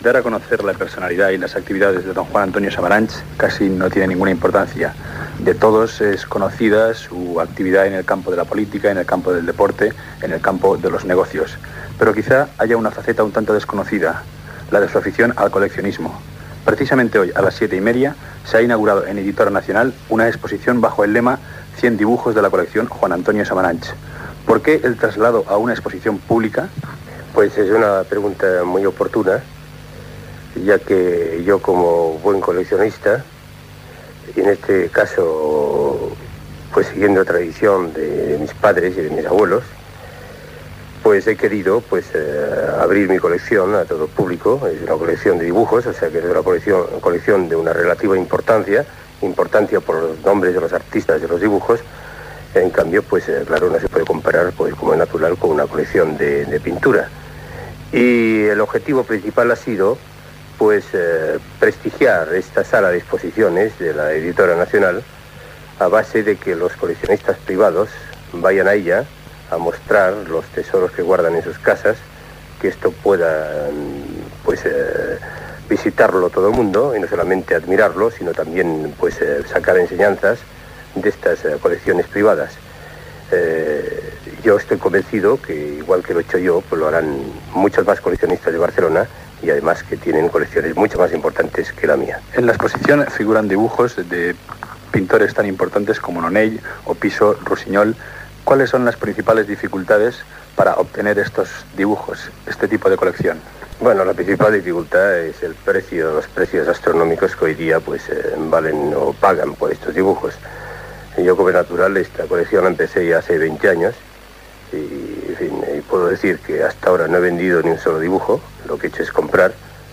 Entrevista a Juan Antonio Samaranch sobre l'exposició "Cien dibujos de la colección Juan Antonio Samaranch" que es pot visitar a la seu d'Editora Nacional
Informatiu